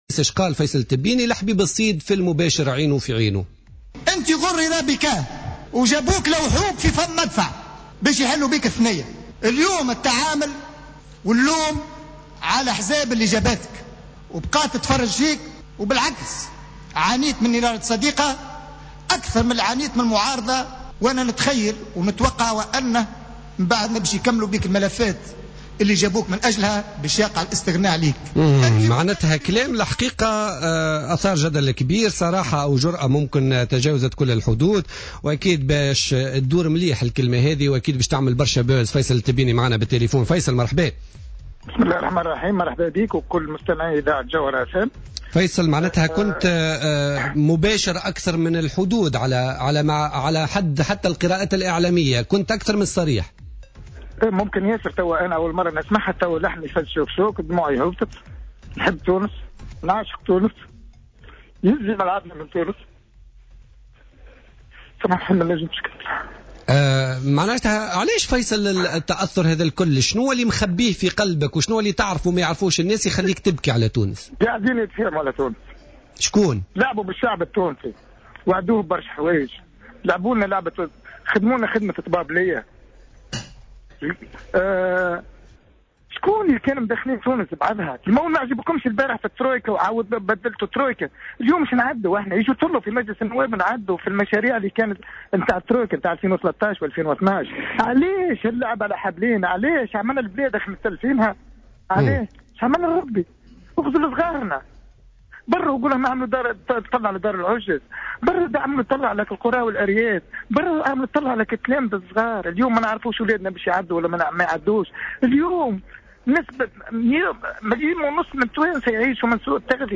النائب فيصل التبيني يبكي من جديد في المباشر
انهار النائب فيصل التبيني بالبكاء في اتصال هاتفي مع "جوهر أف ام" اليوم الجمعة 5 جوان لدى تعليقه على رسالة قوية ومباشرة توجه بها اليوم إلى رئيس الحكومة في جلسة عامة اليوم للاستماع لحكومة الحبيب الصيد.